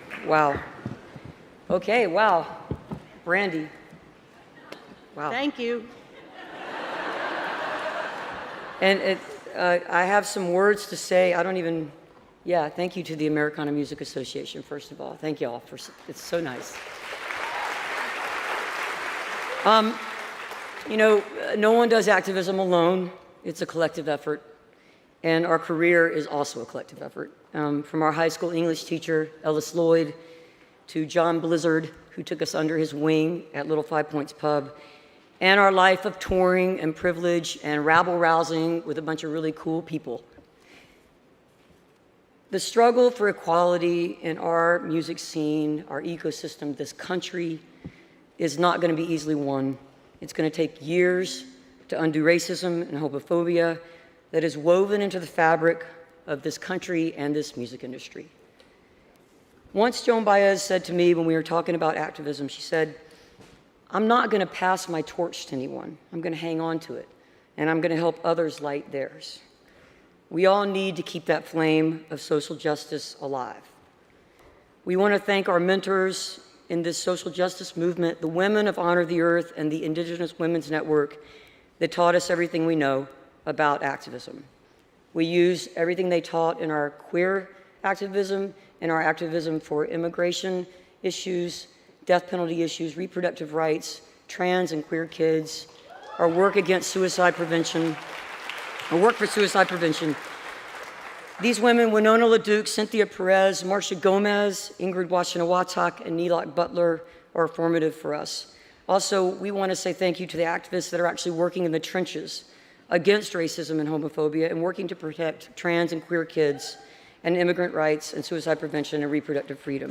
lifeblood: bootlegs: 2022: 2022-09-14: the ryman auditorium - nashville, tennessee (americanafest honors and awards show)
(audio capture from youtube of the presentation of the spirit of americana award for lifetime achievement as songwriters and recording and touring artists)
03. award acceptance - amy ray and emily saliers (4:09)